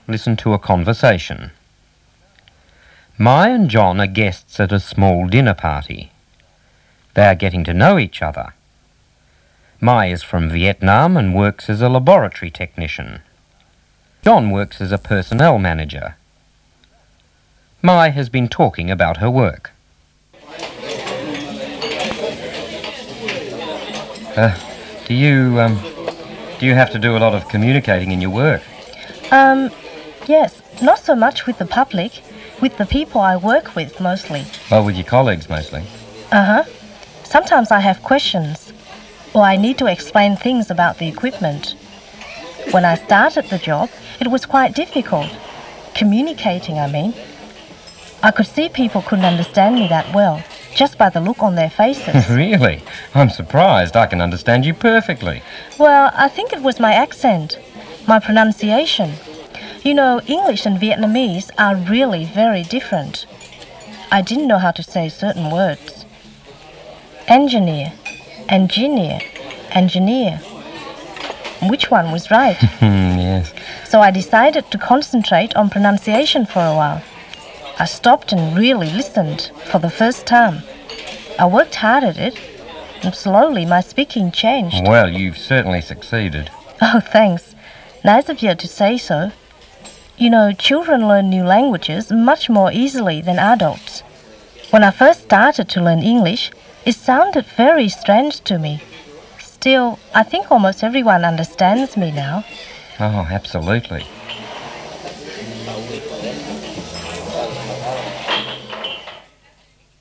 -ed Listening - a conversation
ed-conversation.mp3